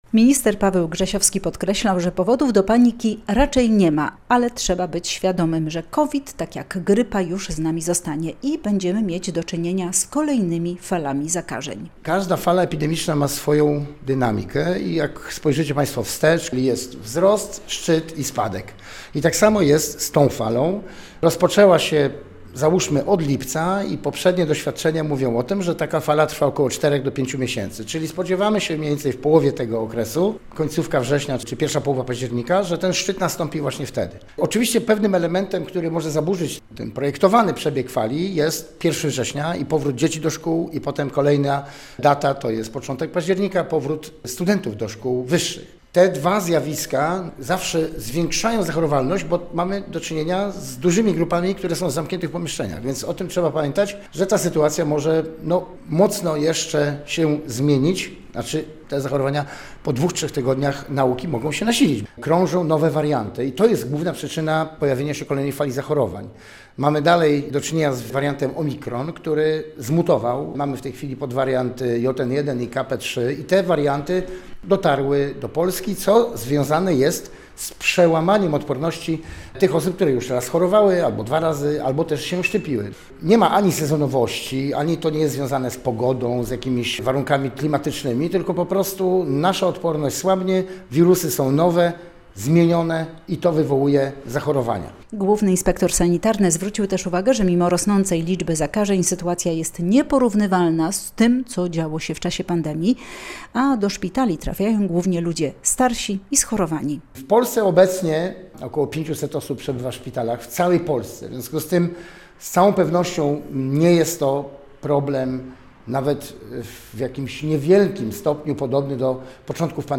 Szczytu zachorowań na COVID-19 w trwającej obecnie fali tej choroby można się spodziewać w październiku. Wtedy też powinna być dostępna najnowsza szczepionka – poinformował w poniedziałek (26.08) na konferencji prasowej w Białymstoku główny inspektor sanitarny dr Paweł Grzesiowski.